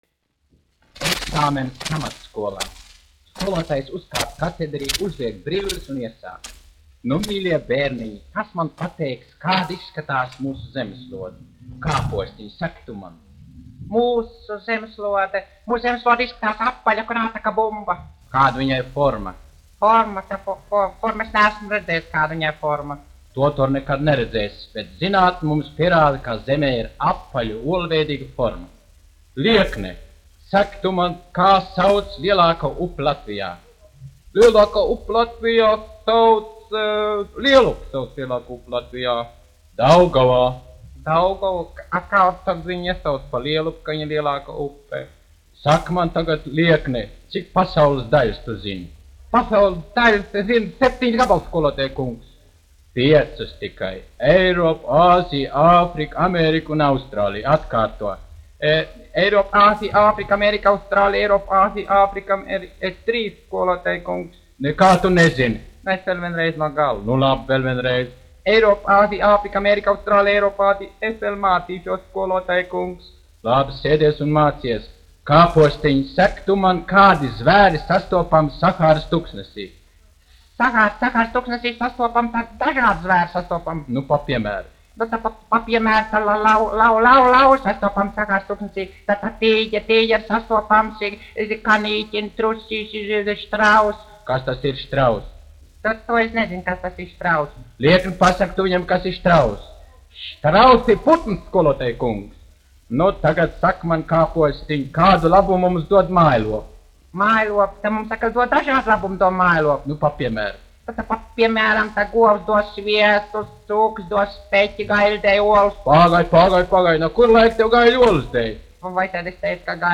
Eksāmens pamatskolā : humoristisks stāstiņš
1 skpl. : analogs, 78 apgr/min, mono ; 25 cm
Skaņuplate
Latvijas vēsturiskie šellaka skaņuplašu ieraksti (Kolekcija)